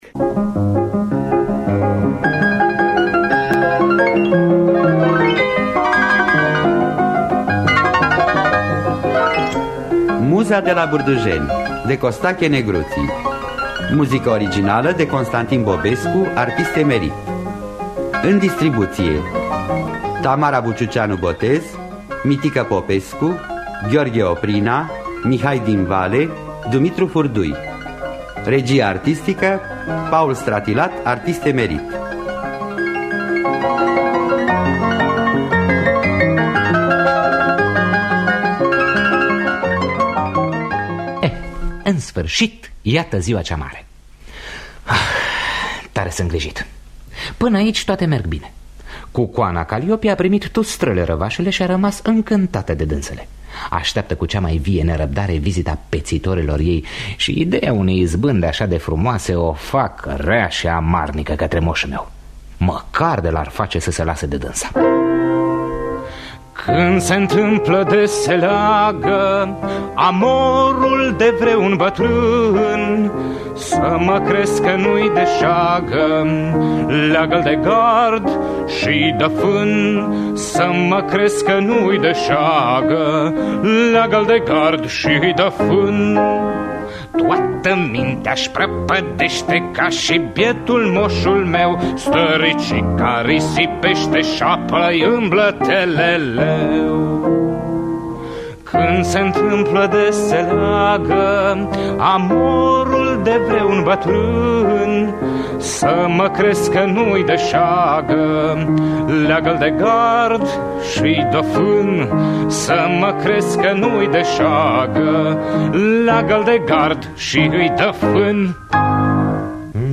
Adaptarea radiofonică
Cântă la pian